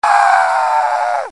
Uomo che urla
Urlo uomo.
Effetto sonoro - Uomo che urla